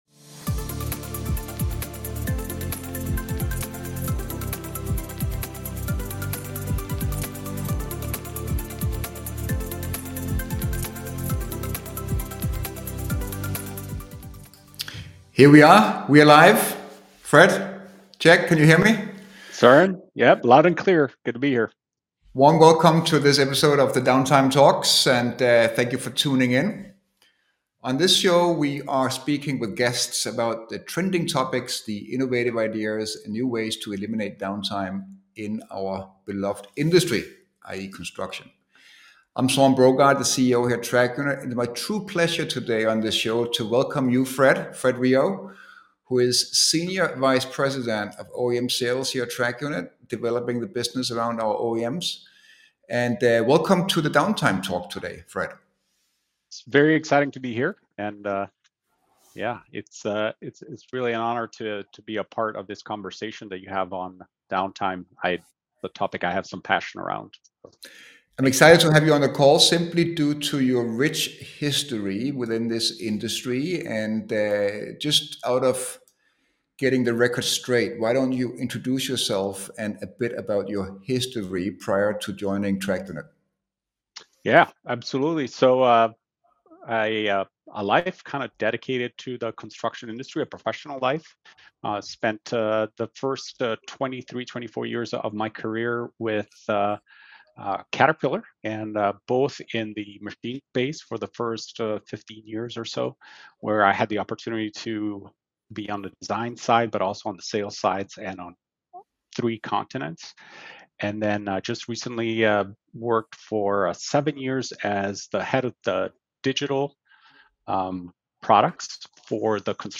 A conversation about how ambitions are changing and what kind of people OEMs need to have at the table to not fall short on their digital journey.